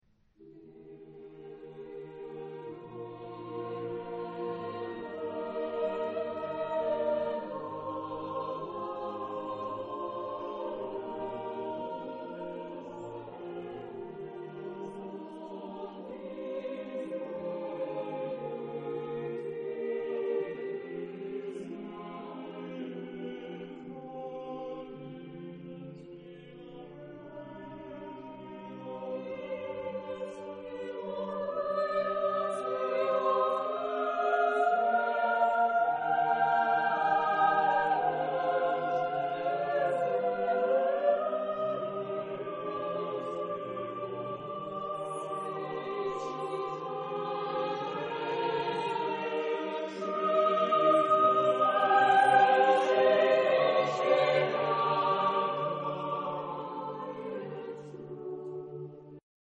Genre-Stil-Form: Madrigal ; weltlich
Charakter des Stückes: glänzend
Chorgattung: SSAATTBB  (8 gemischter Chor Stimmen )
Tonart(en): Es-Dur
Aufnahme Bestellnummer: Internationaler Kammerchor Wettbewerb Marktoberdorf
Lokalisierung : Romantique Profane Acappella